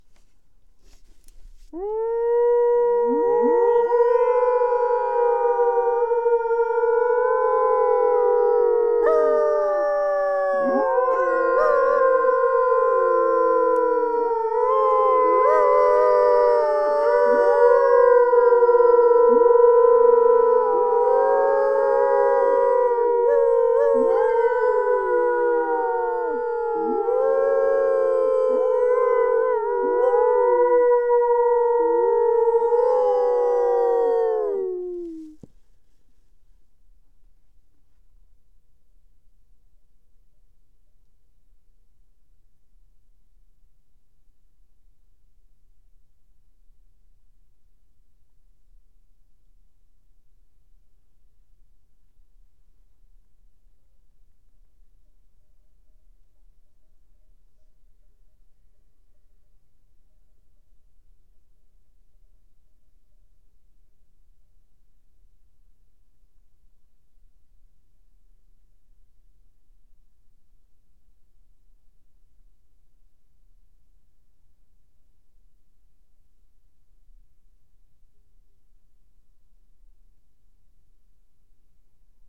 Le hurlement provoqué : technique de suivi historique, importée et adaptée à partir des suivis réalisés outre atlantique. Cette méthode, basée sur le réflexe territorial de réponse des loups à un hurlement imité par l’homme (enregistrement ci-dessous), est mise en œuvre depuis le début des années 2000 par le Réseau de suivi pour détecter la reproduction et ainsi identifier les meutes reproductrices françaises.
Une équipe terrain en train d’emettre un séquence d’hurlement © Réseau loup-lynx
4_hurleurs.mp3